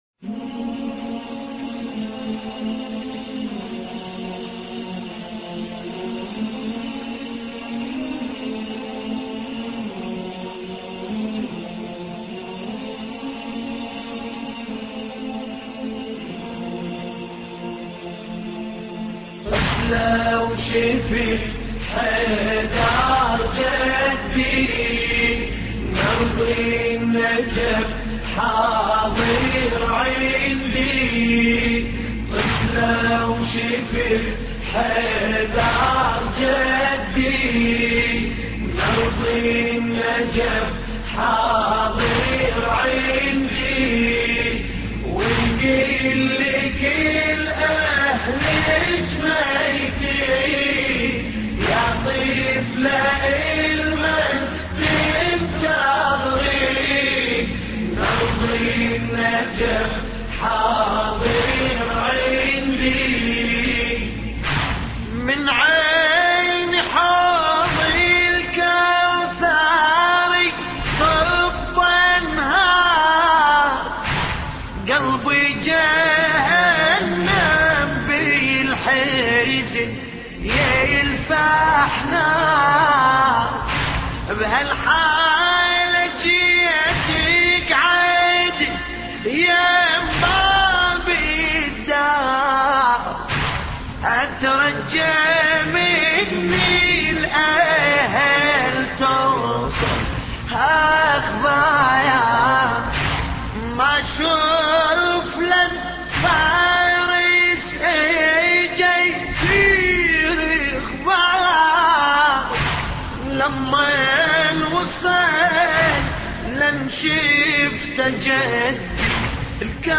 مراثي أهل البيت (ع)